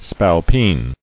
[spal·peen]